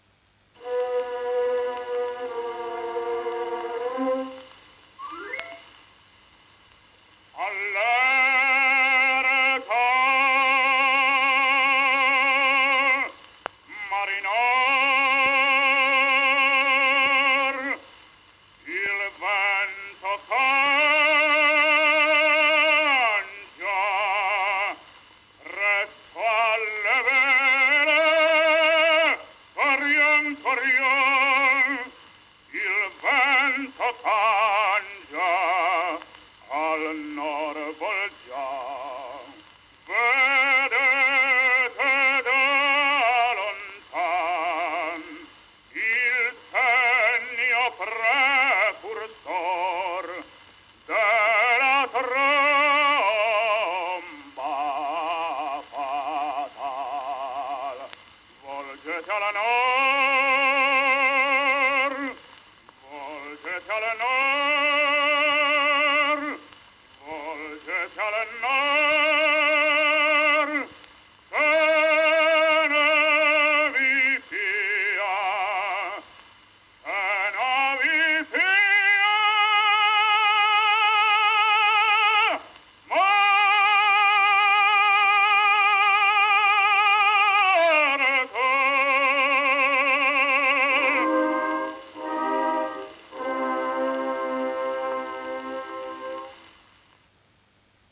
Sotto ascolterete tre brani da incisioni acustiche, e una registrazione dove scherza con la voce di Chaliapin.